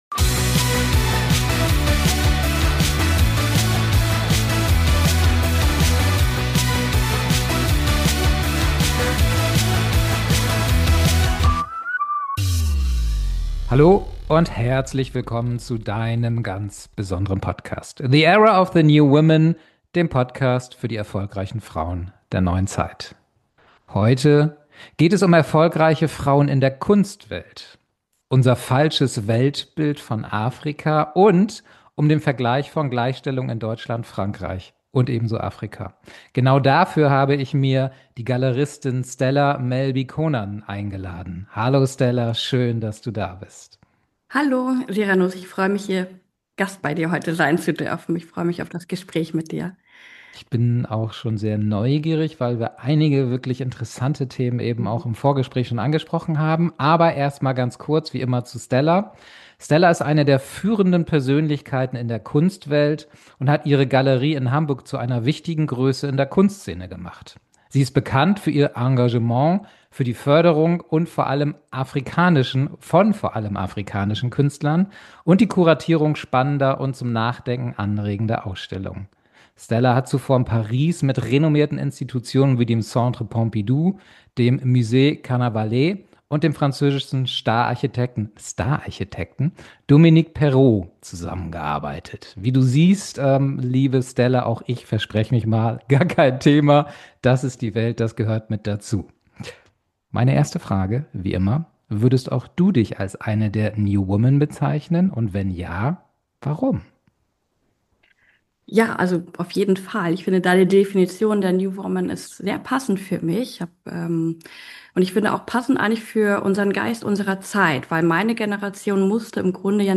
Ein Gespräch über Vision, Leidenschaft und den Mut, etwas zu schaffen, das größer ist als man selbst.